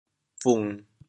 How to say the words 分 in Teochew？
bung1.mp3